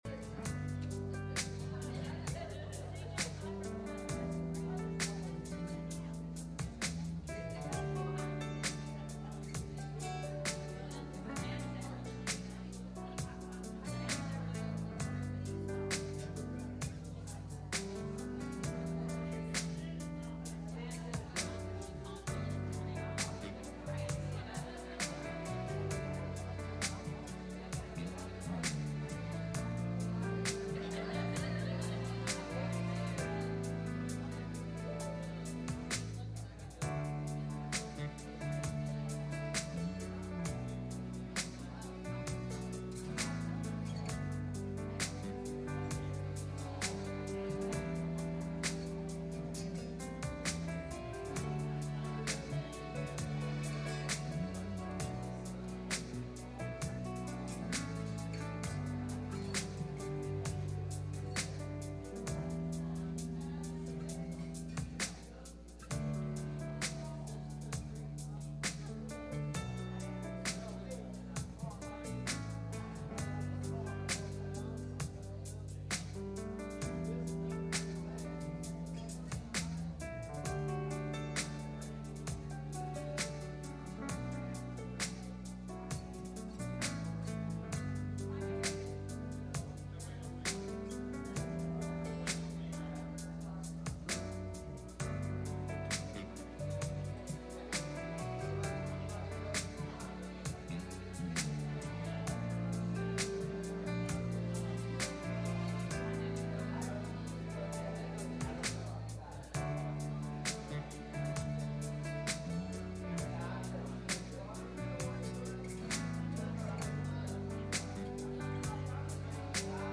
Genesis 42:1-5 Service Type: Sunday Morning «